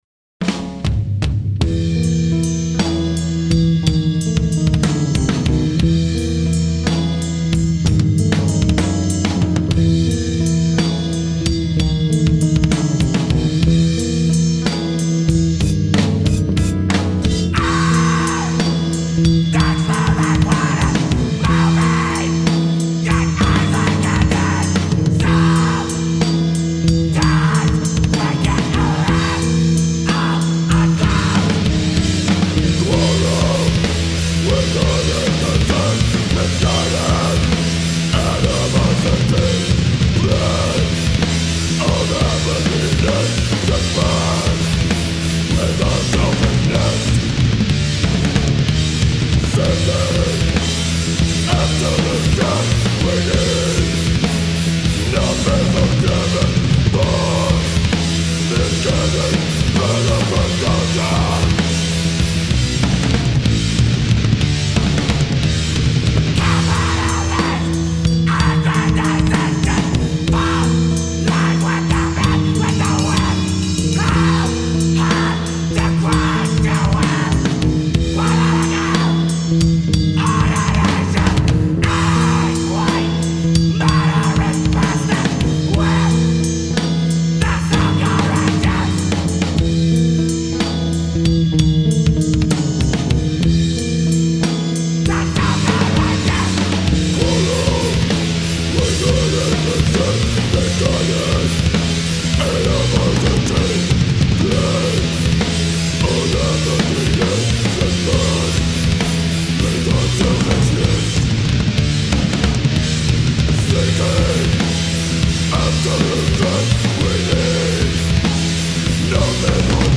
hardcore jazz metal band